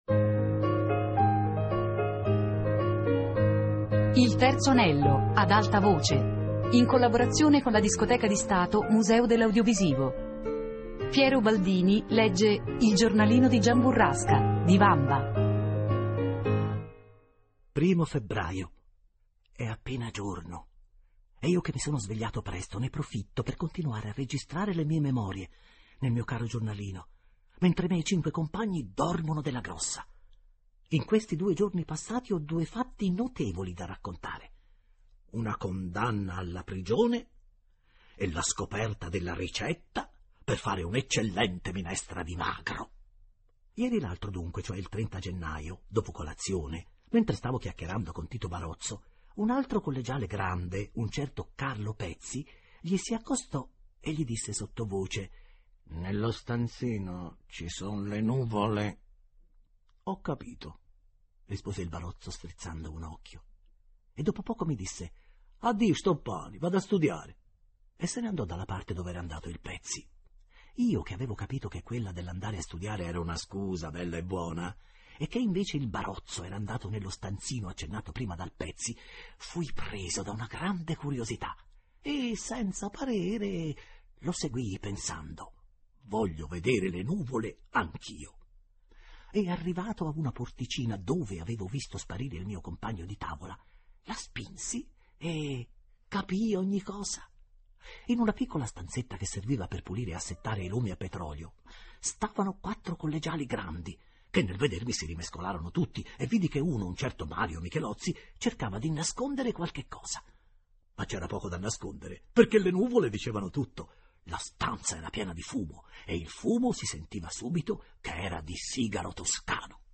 Il giornalino di Giamburrasca - Lettura XIV